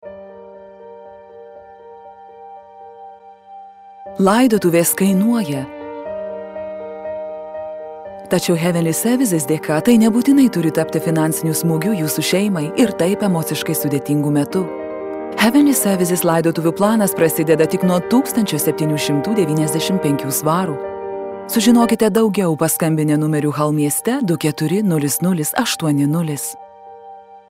Lithuanian - Female
Commercial, Warm, Soft, Reassuring